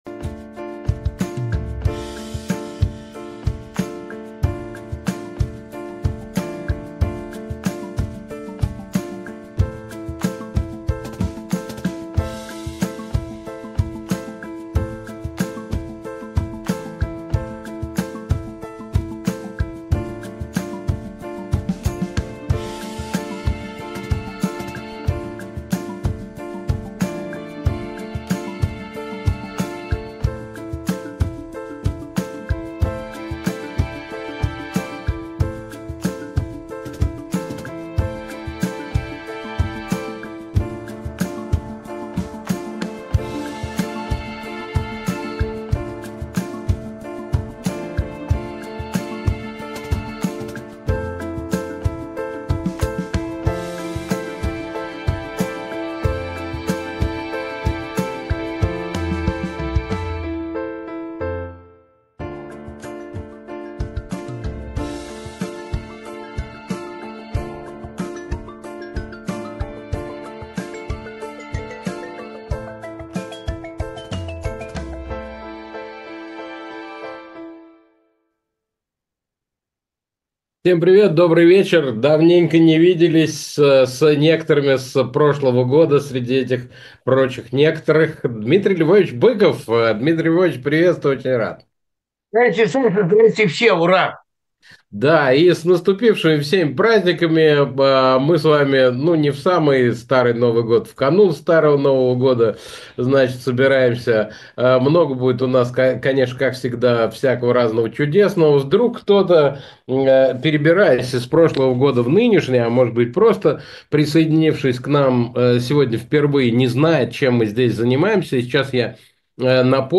Эфир ведут Александр Плющев и Дмитрий Быков